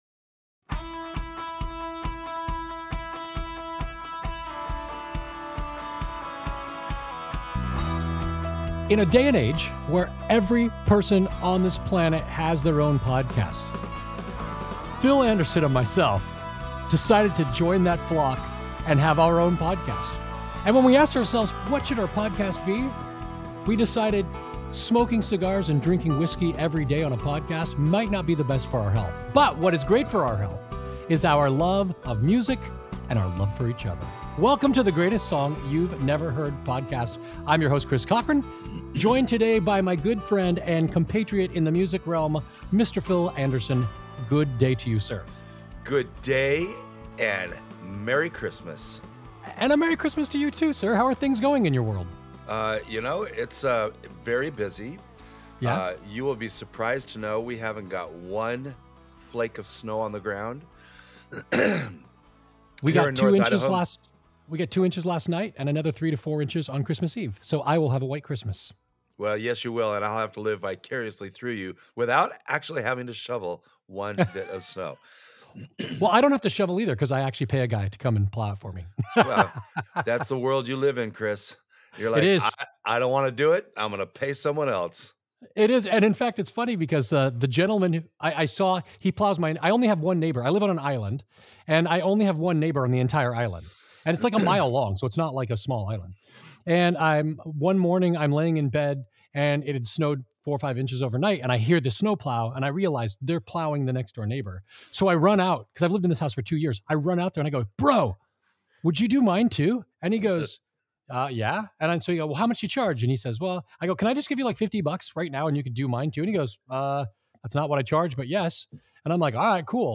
But maybe…JUST maybe, most people haven’t heard THIS fun and playful version, full of overproduced drama.